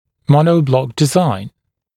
[‘mɔnəuˌblɔk dɪ’zaɪn][‘моноуˌблок ди’зайн]моноблочная конструкция